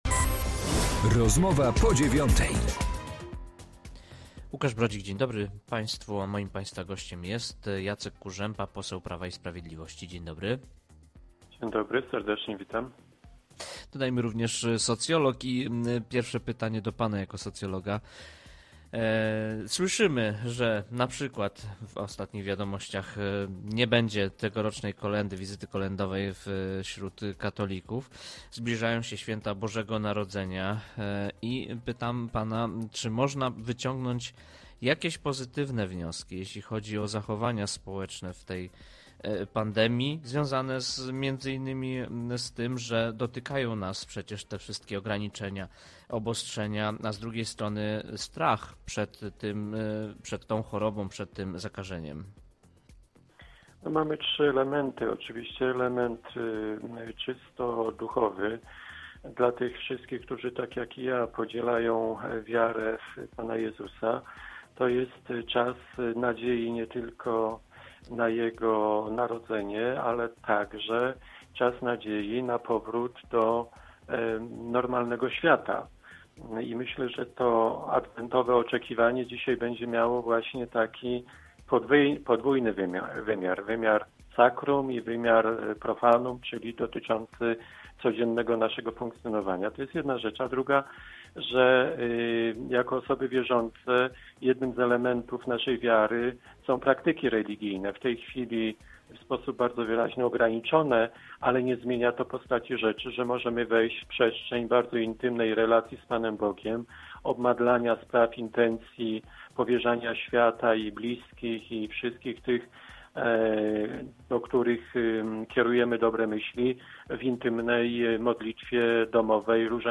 Z posłem Prawa i Sprawiedliwości rozmawia